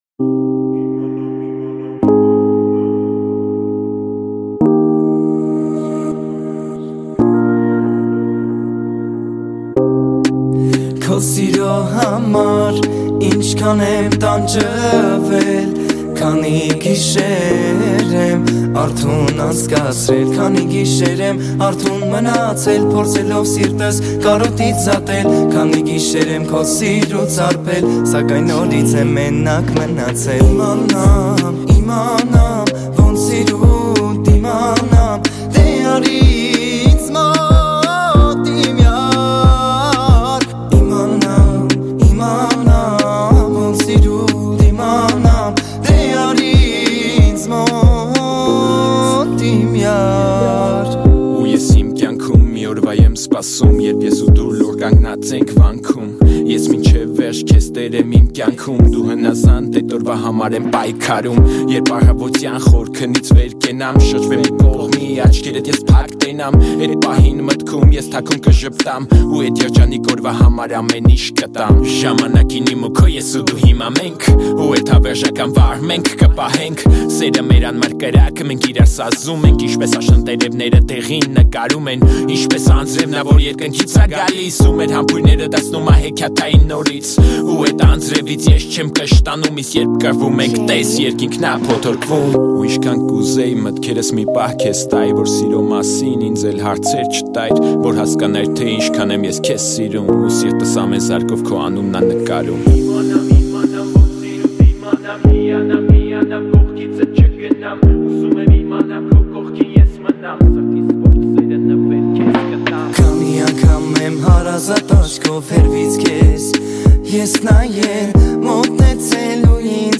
Армянская музыка